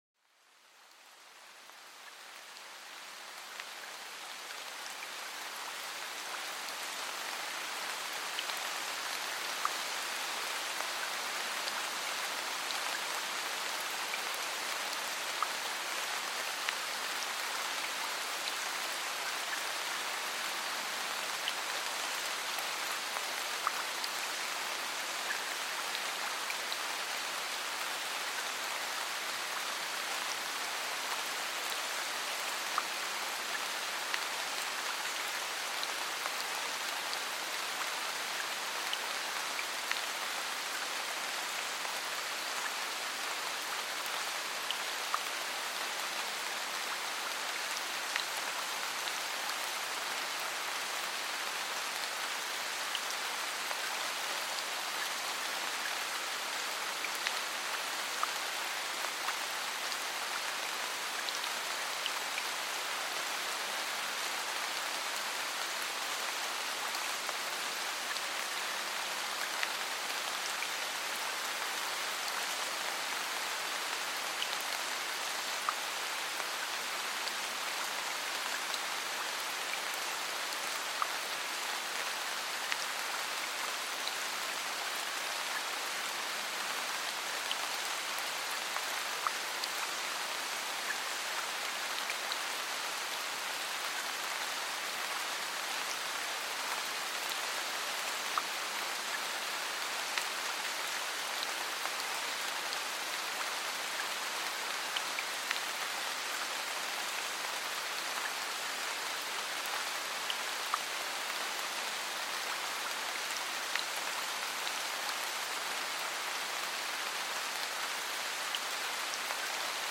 Sumérgete en el sonido reconfortante de la lluvia, una melodía natural que envuelve la mente y calma.Explora cómo los suaves toques de la lluvia sobre la tierra pueden revitalizar nuestro mundo interior e inspirar tranquilidad.Deja que este viaje sonoro te guíe a través de los beneficios terapéuticos de la lluvia, un verdadero bálsamo para el alma.Este podcast es una experiencia de audio inmersiva que sumerge a los oyentes en los maravillosos sonidos de la naturaleza.